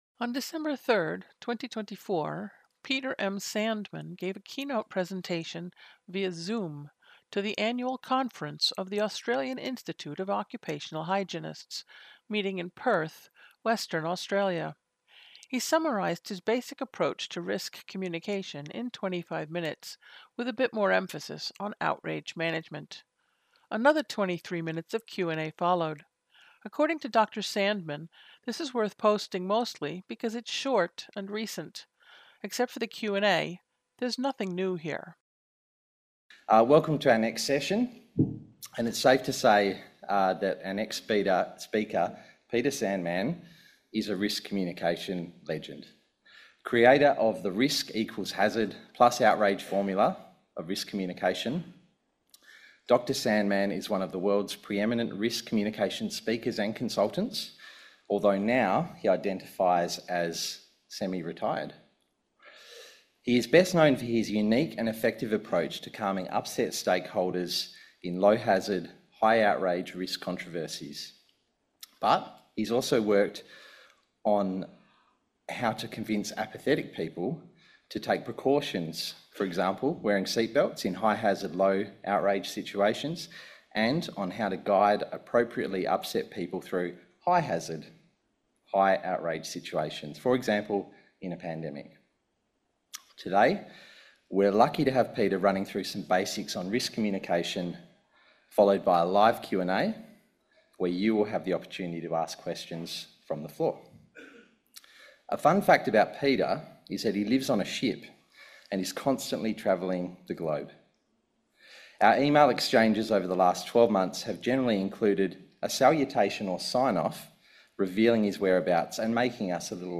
Keynote presentation via Zoom for the Australian Institute of Occupational Hygienists, Perth, Western Australia, December 3, 2024
I managed to summarize my basic approach to risk communication in 25 minutes or so (with a bit more emphasis on outrage management). Another 23 minutes of Q&A followed.